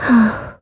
Ripine-Vox_Sigh_kr_02.wav